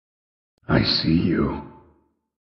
i see you fnaf springtrap Meme Sound Effect